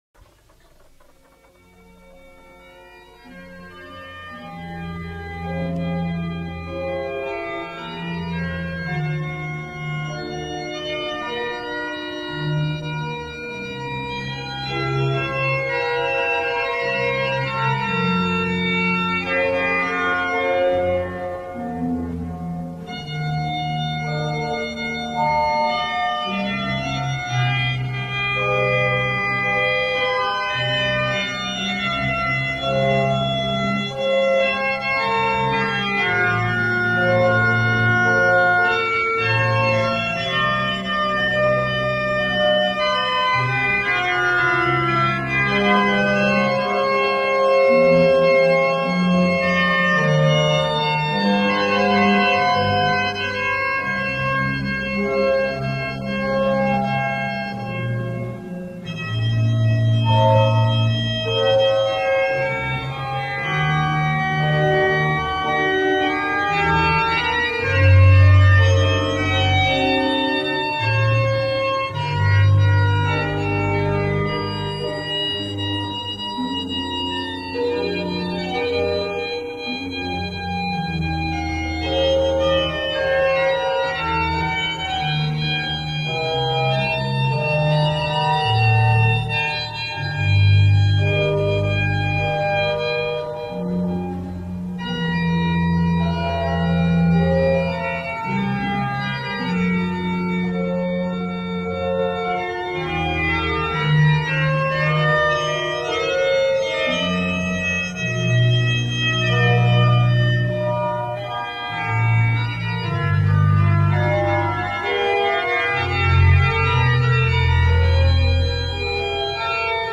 Música Johann Sebastian Bach
Adecuada utilización de etéreas y ceremoniosas partituras de Johann Sebastian Bach.
tree-of-wooden-clogs-soundtrack-bach-fugue-in-g-minor.mp3